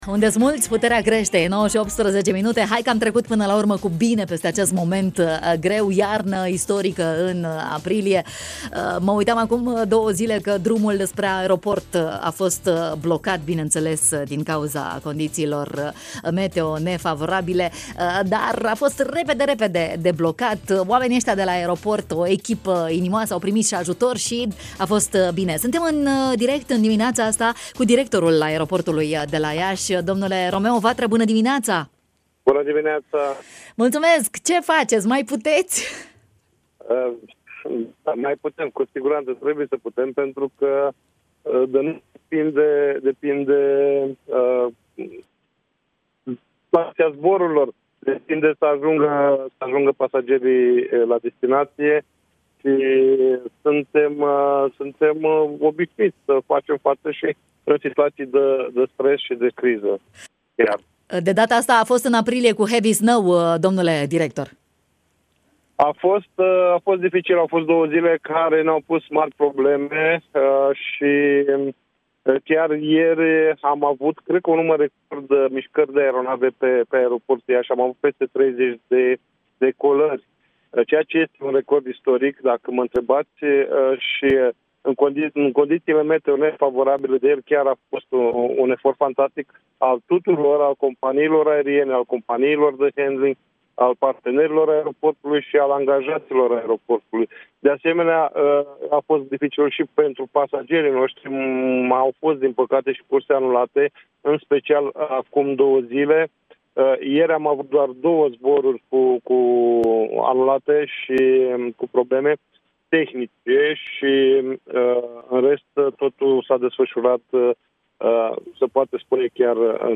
În direct, prin telefon la matinalul de la Radio România Iași